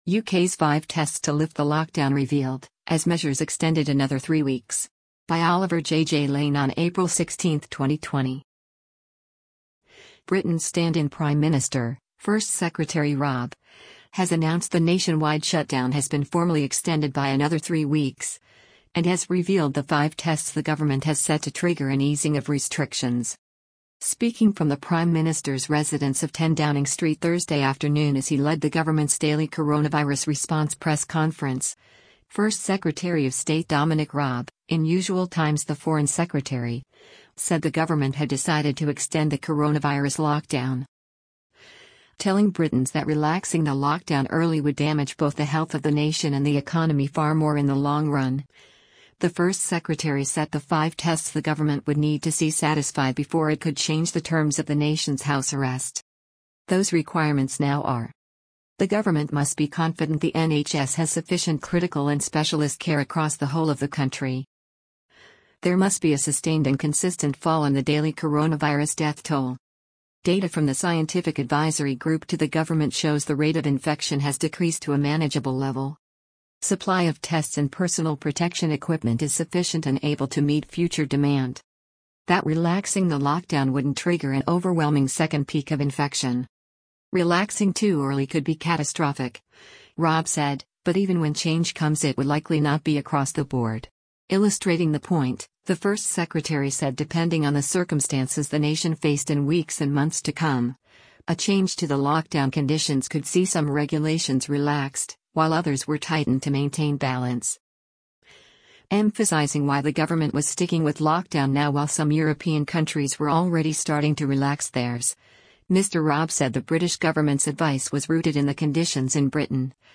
Speaking from the Prime Minister’s residence of 10 Downing Street Thursday afternoon as he led the government’s daily coronavirus response press conference, First Secretary of State Dominic Raab — in usual times the Foreign Secretary — said the government had decided to extend the coronavirus lockdown.